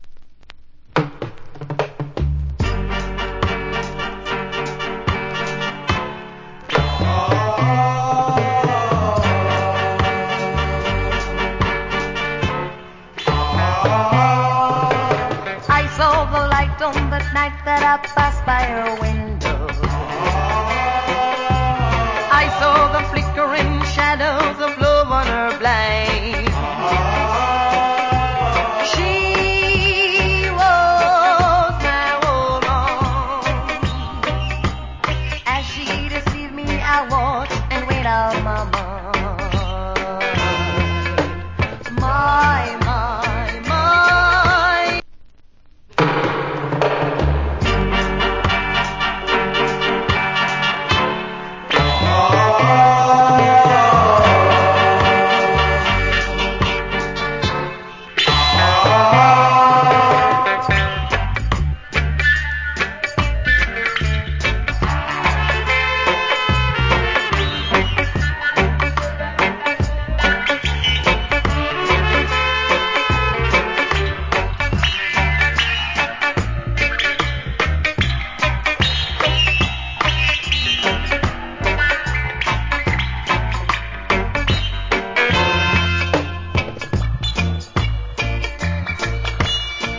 Cool Roots.